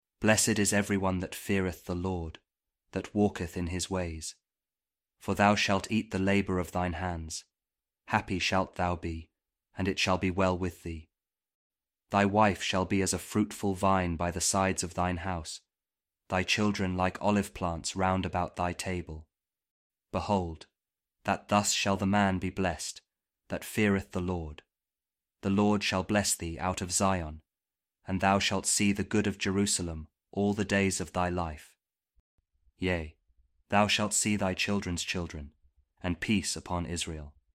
Psalm 128 | King James Audio Bible
psalm-128-kjv-king-james-audio-bible-word-aloud.mp3